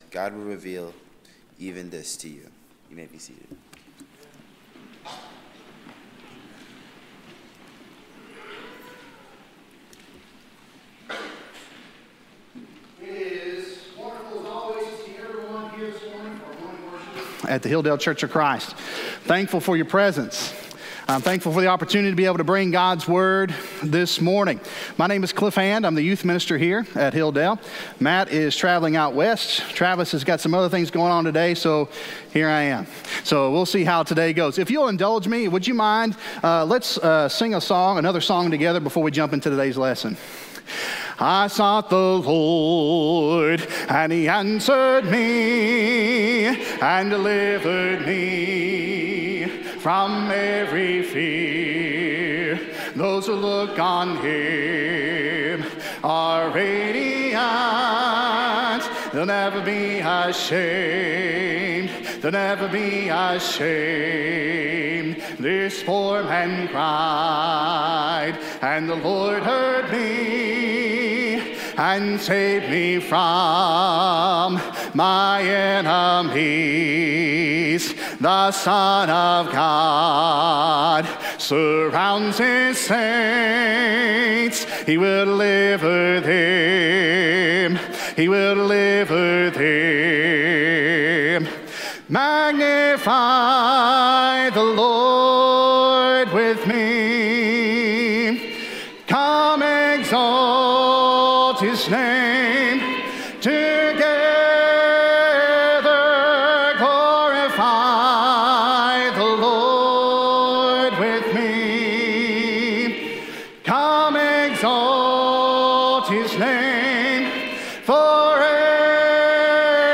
This podcast has been created to provide you with the audio from our weekly lessons.